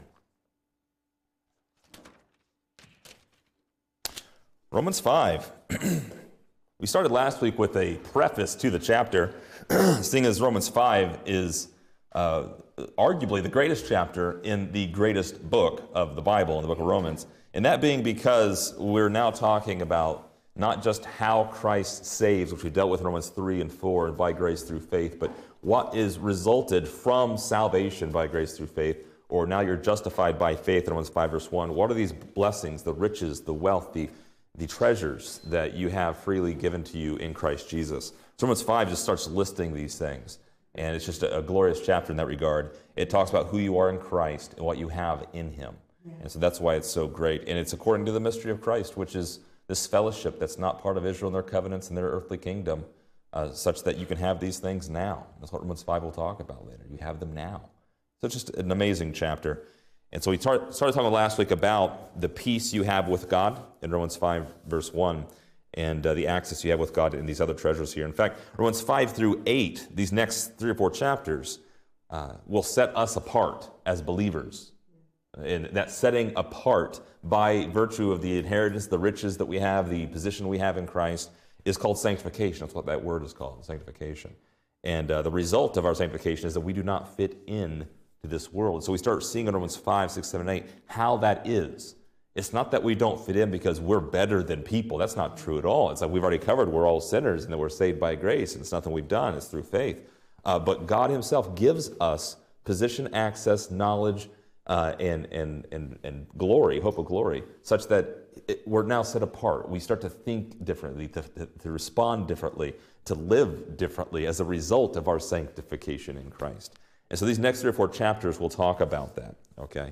Description: This lesson is part 32 in a verse by verse study through Romans titled: Glory in Tribulations.